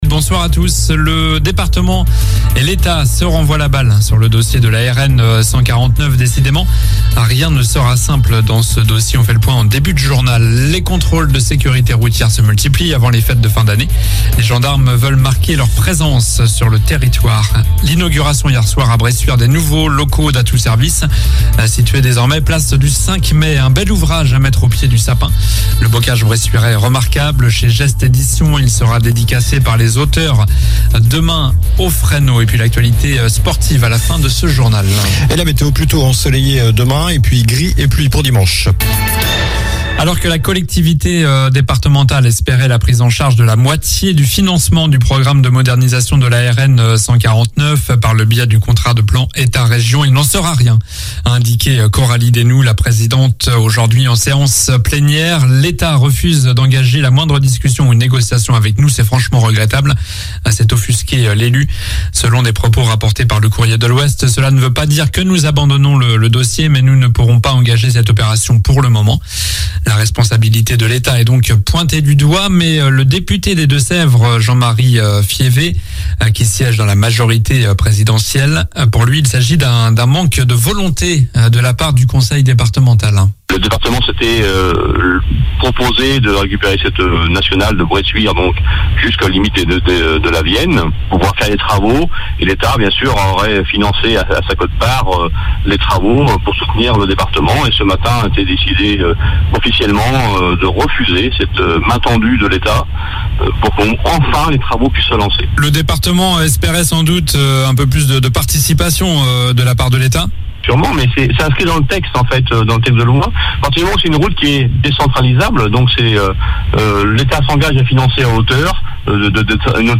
Journal du vendredi 16 décembre (soir)